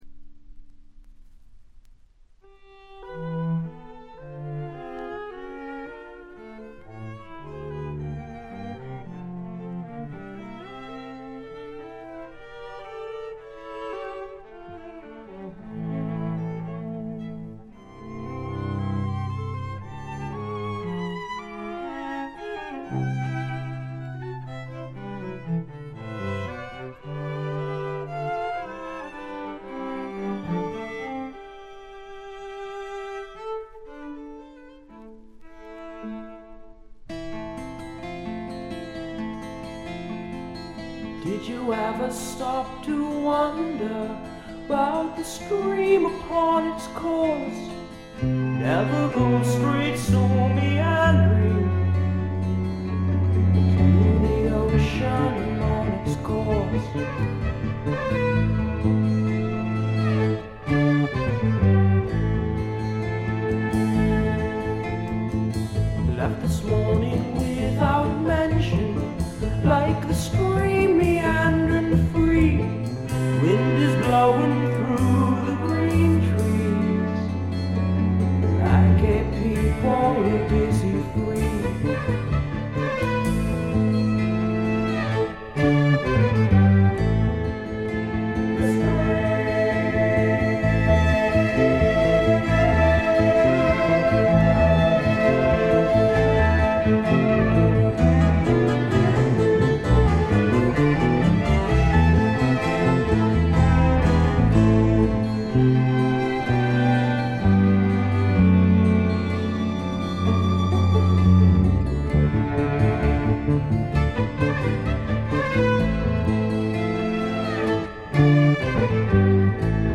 米国産クラシカル・プログレッシブ・フォークの名作です。
試聴曲は現品からの取り込み音源です。